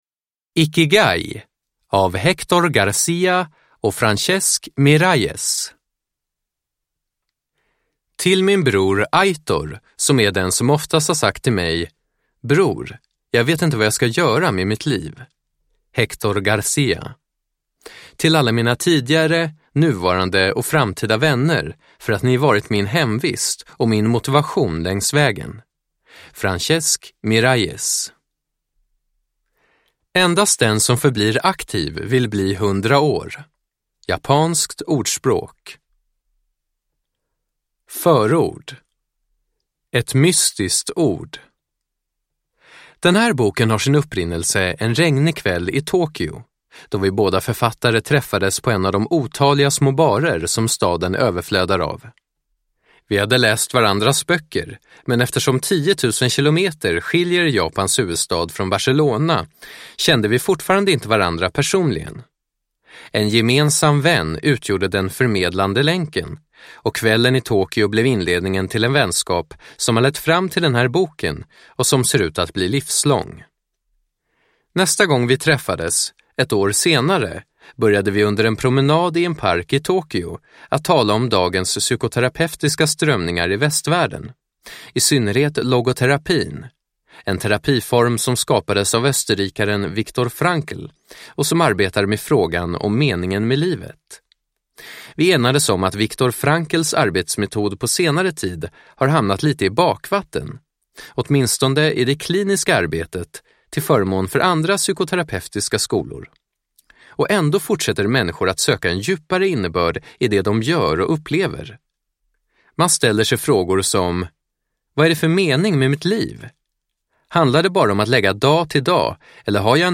Ikigai : den japanska livskonsten till ett långt och lyckligt liv – Ljudbok – Laddas ner